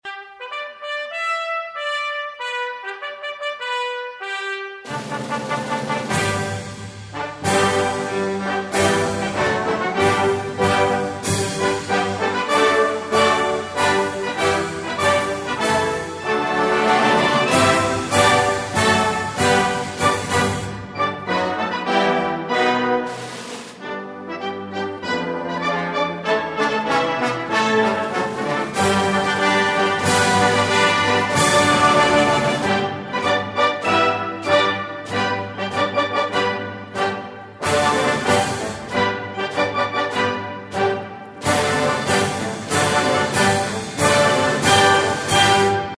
《义勇军进行曲》高昂激越、铿锵有力，表达了中国人民奋不顾身争取民族解放的决心，体现了中华民族勇敢、坚强、团结御侮的光荣传统。